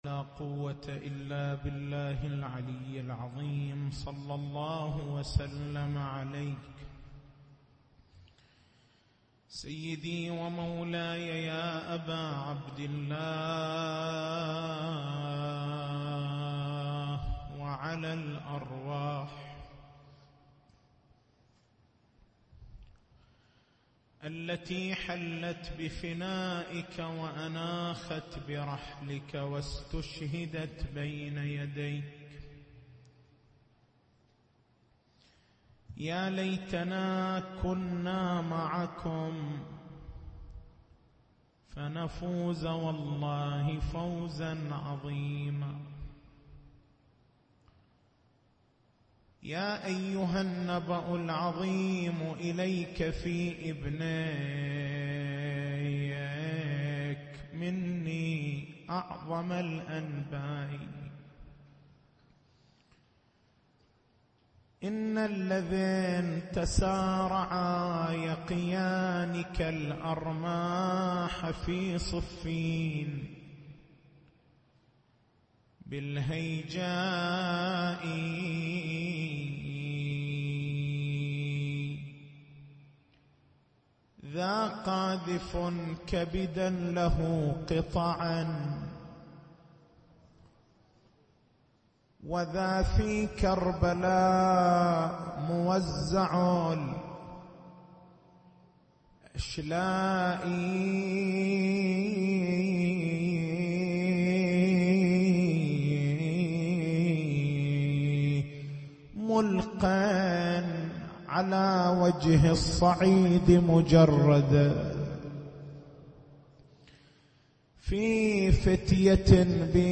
تاريخ المحاضرة: 17/09/1435 نقاط البحث: ما هو المقصود من الطهارة؟ ما هو وجه إثبات الطهارة بعد نفي الرجس؟